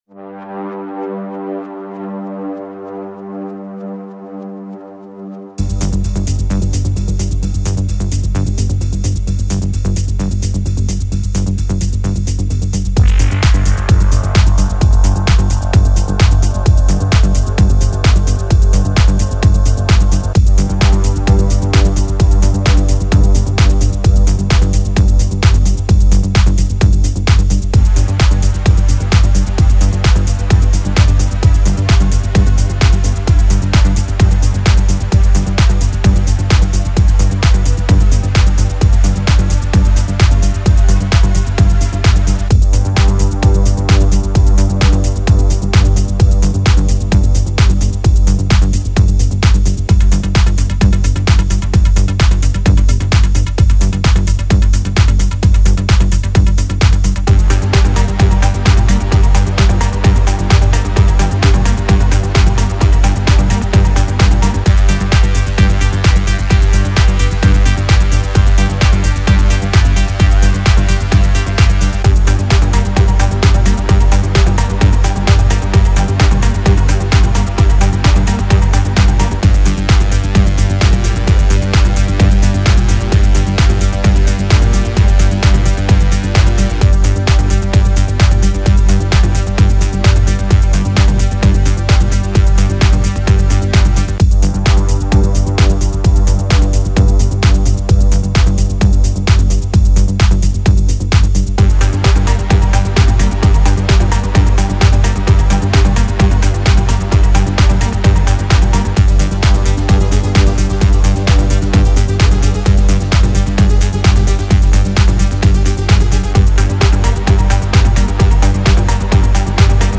trance
Звук, как всегда - топ!
Ого-го так бодренько, зачётик!